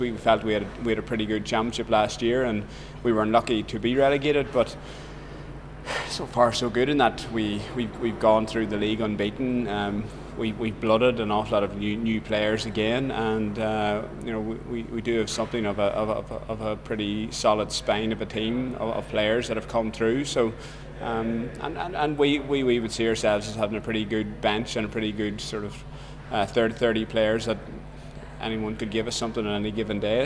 Sean Cavanagh of Tyrone speaks to U105